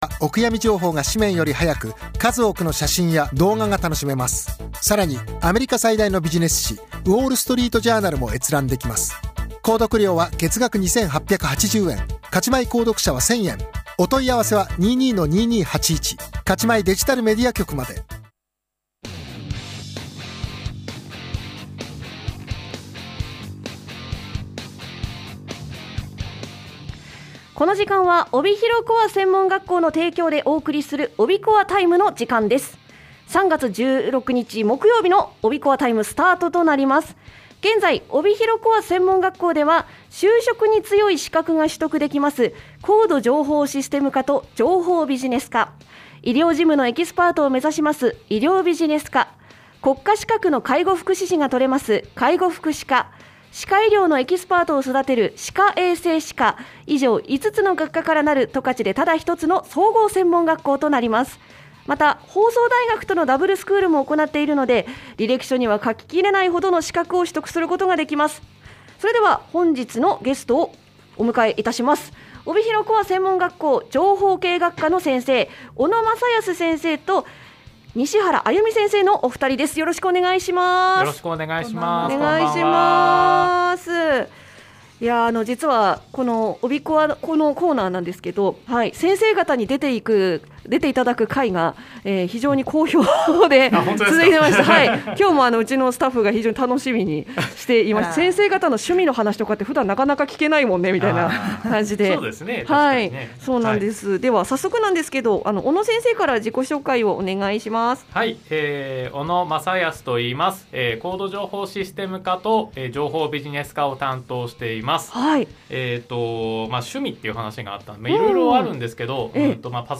楽しいトークをお聞きください！